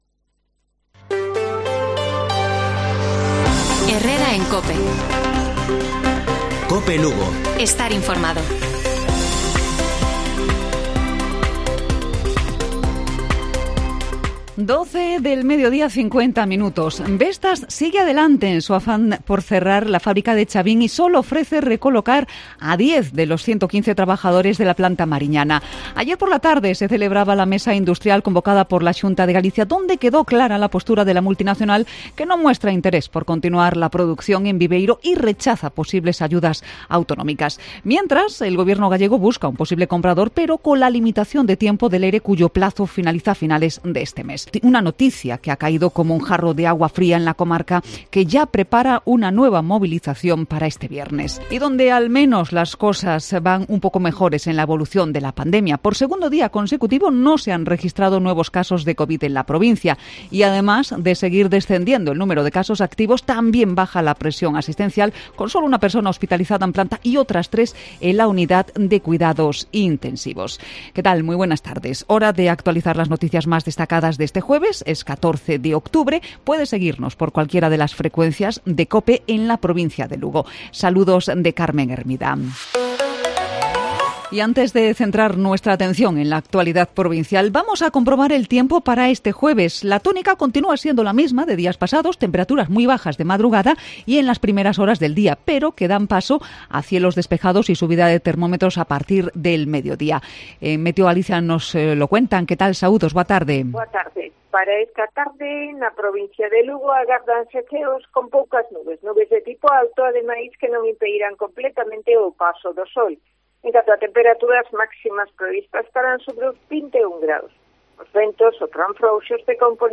Informativo Provincial Cope Lugo.